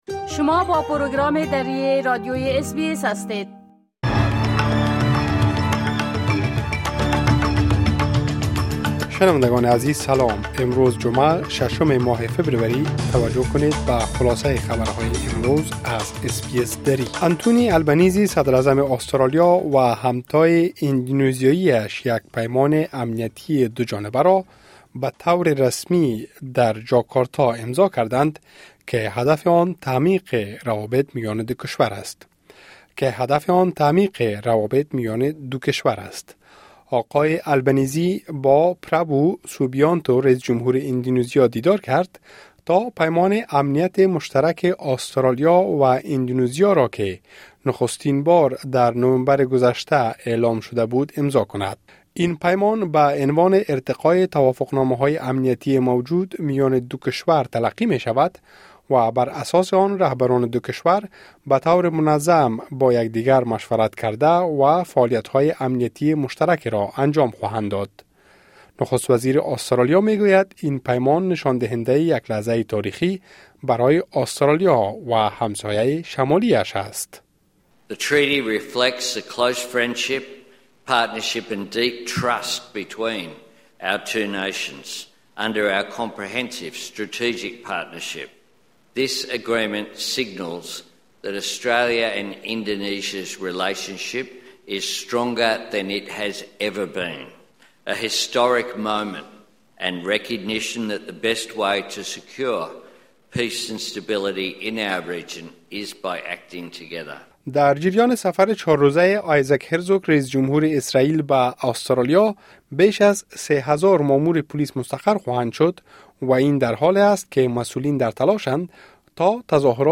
خلاصه‌ای مهم‌ترين خبرهای روز | ۶ فبروری